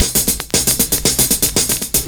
112CYMB09.wav